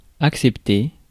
Ääntäminen
Synonyymit permis Ääntäminen France: IPA: [ak.sɛp.te] Haettu sana löytyi näillä lähdekielillä: ranska Käännös Ääninäyte Adjektiivit 1. accepted US 2. permitted US Suku: m .